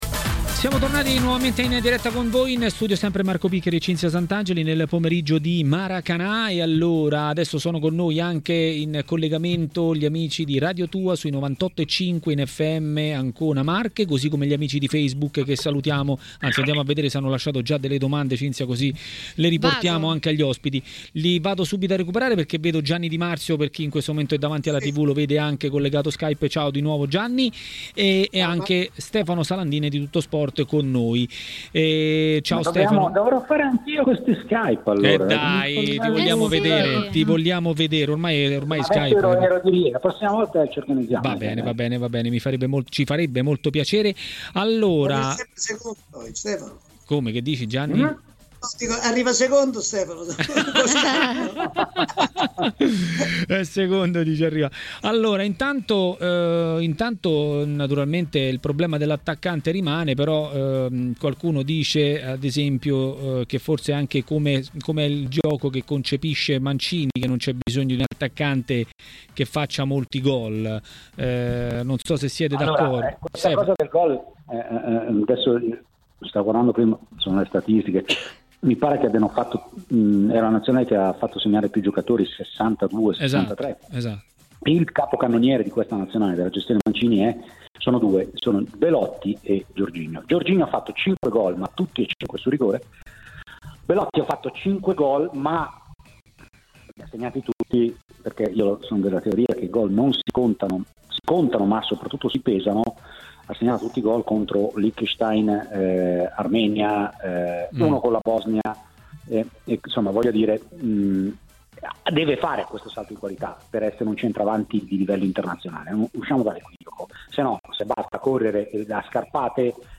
nel pomeriggio di TMW Radio
TMW Radio Regia Ascolta l'audio Ospiti